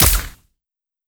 Hitech Shot C.wav